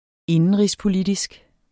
Udtale [ ˈenənʁis- ]